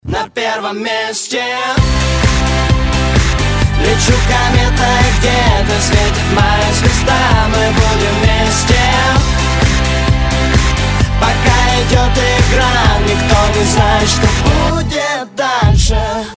Поп музыка, Eurodance